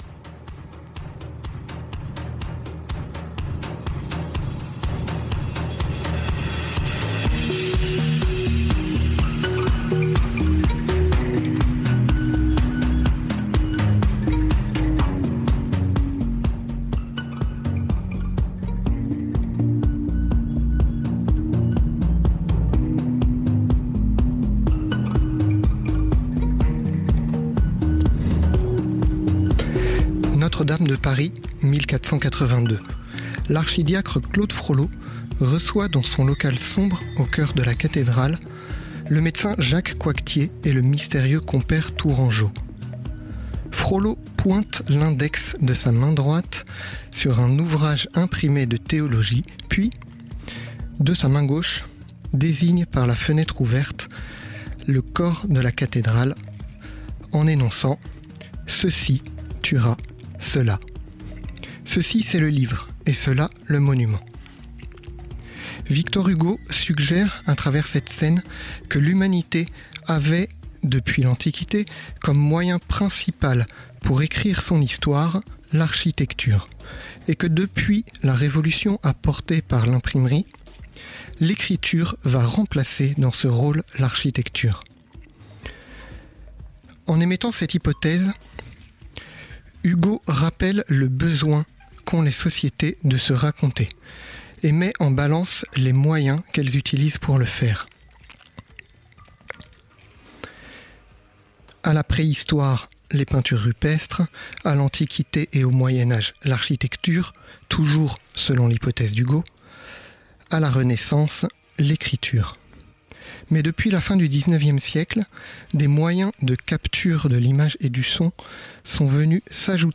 Partager Type Entretien Éduc Pop Sciences Société Autour du globe Autre jeudi 22 septembre 2022 Lire Pause Télécharger Une base zapatiste à Chiapas, Mexique.